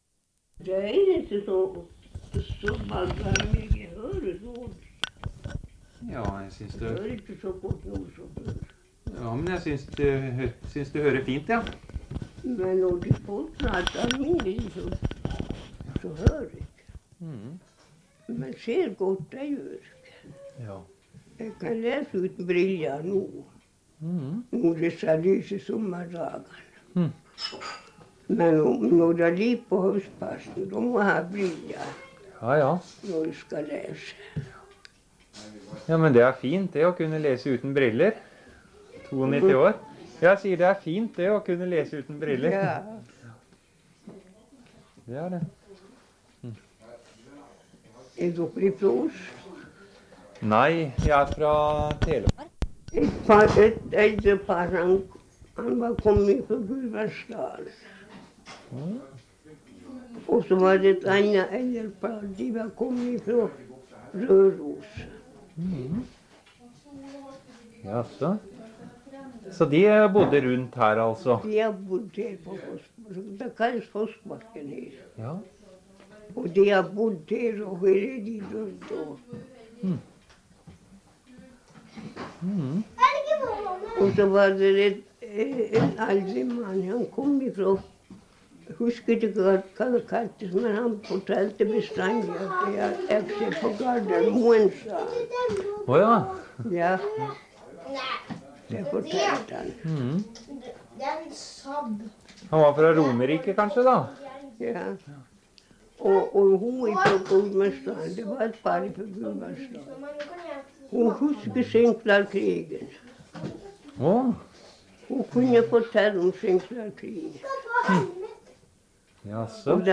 Sted: Lavangen, Fossbakken
Intervjuer